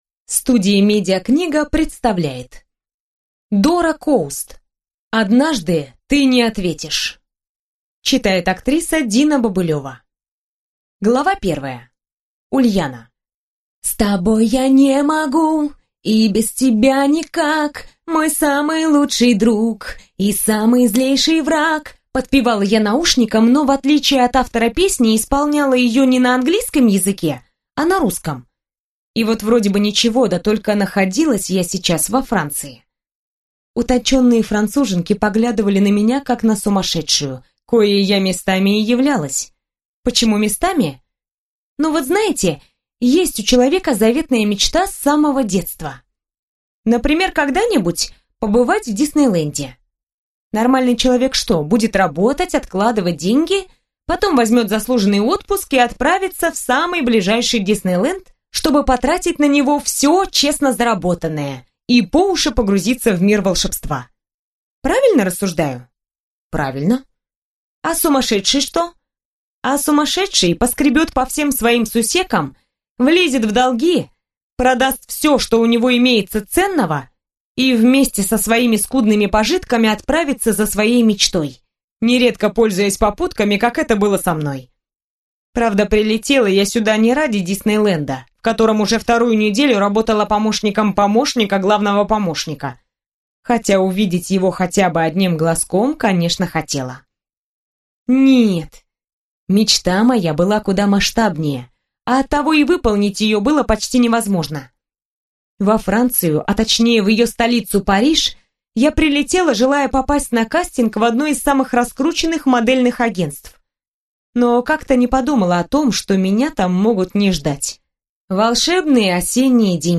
Аудиокнига Однажды ты не ответишь | Библиотека аудиокниг